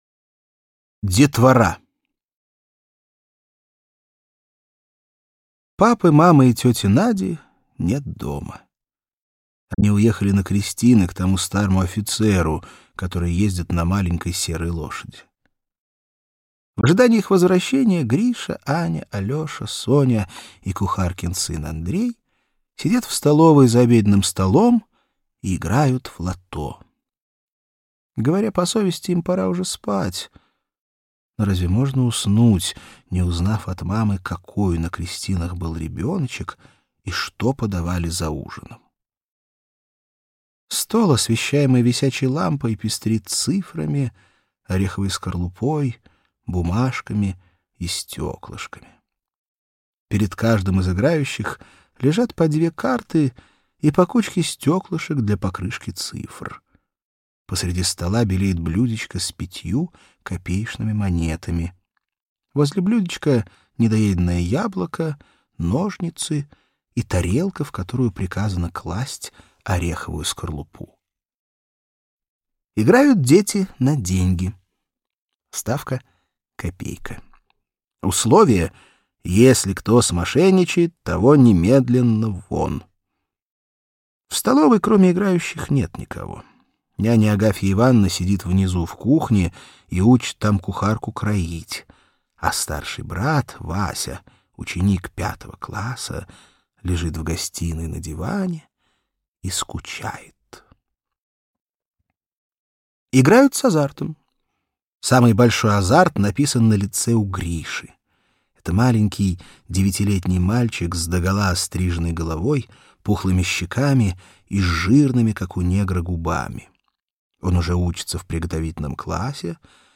Аудиокнига Русские классики детям | Библиотека аудиокниг
Aудиокнига Русские классики детям Автор Антон Чехов Читает аудиокнигу Алексей Грибов.